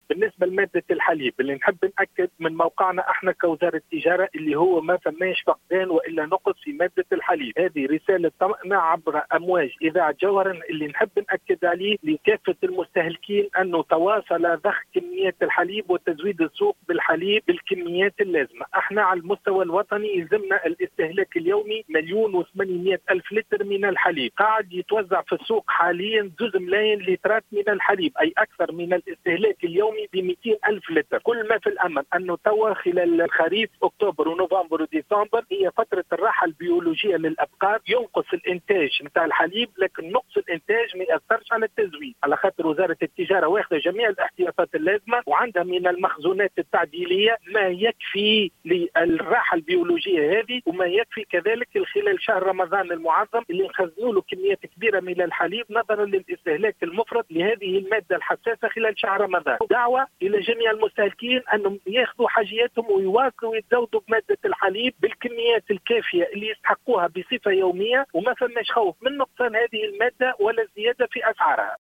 نفى مدير عام المنافسة والأبحاث الاقتصادية بوزارة التجارة، ياسر بن خليفة، اليوم السبت وبشكل قاطع في تصريح لـ"الجوهرة اف أم" أي نقص أو فقدان لمادة الحليب بالأسواق.